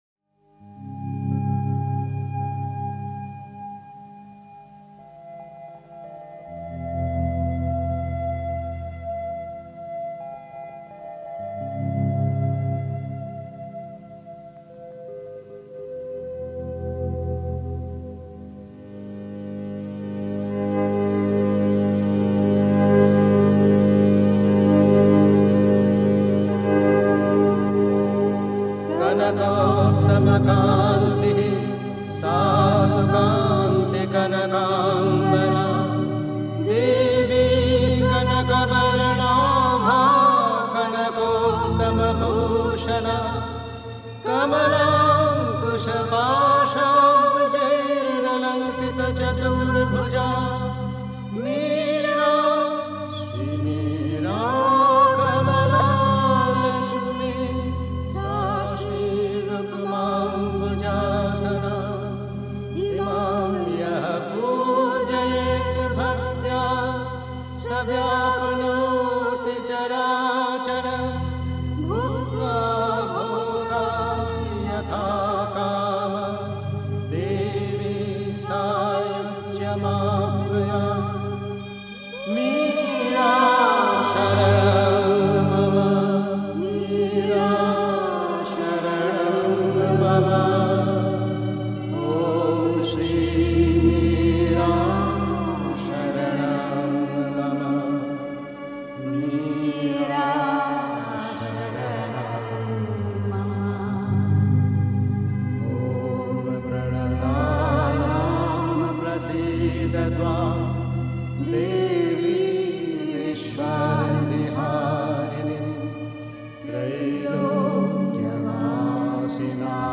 Das Göttliche tut die Sadhana zuerst für die Welt (Sri Aurobindo, CWSA Vol. 32, p. 329) 3. Zwölf Minuten Stille.